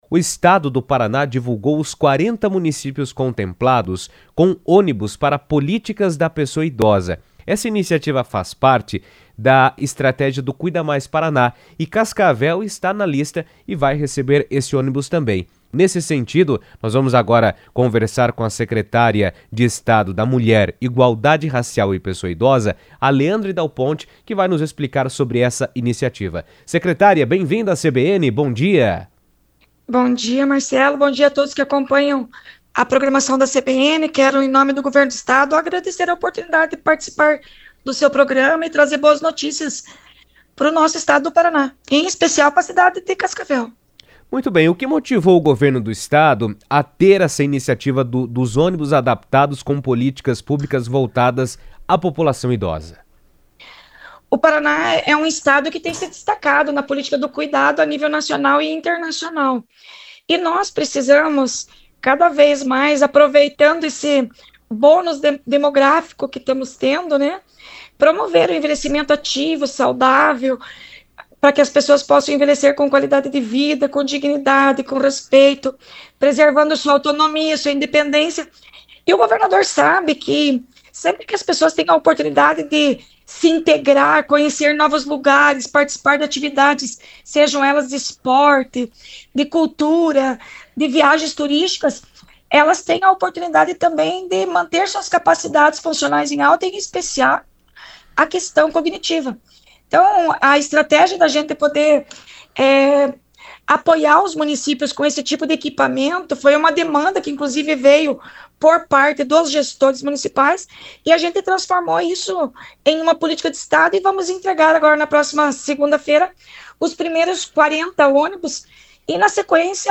A iniciativa integra o programa Cuida Mais Paraná, que busca ampliar o acesso da população idosa a serviços públicos, além de promover atividades culturais, esportivas e turísticas que incentivem a integração e o convívio social. Sobre a ação e os benefícios esperados, Leandre Dal Ponte, secretária da Mulher, Igualdade Racial e Pessoa Idosa, comentou ao vivo por telefone na CBN.